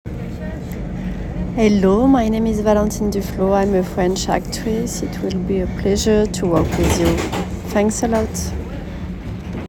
Présentation en anglais